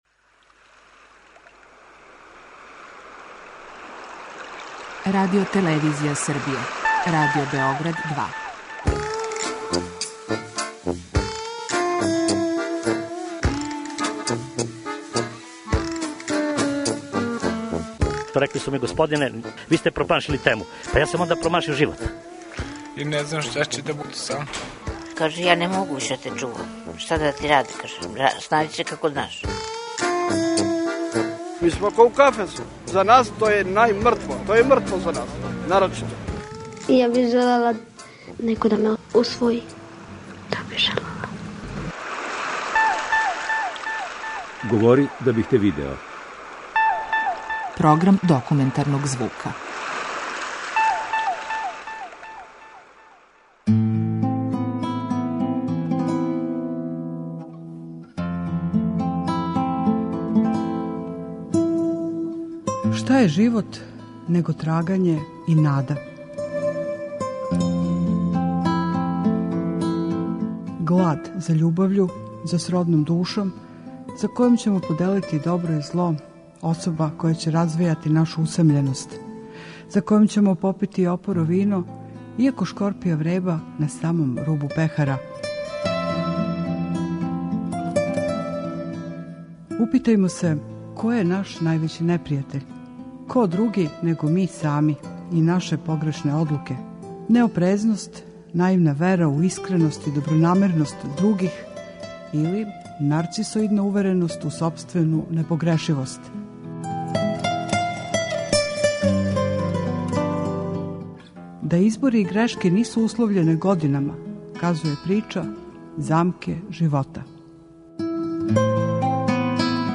Документарни програм.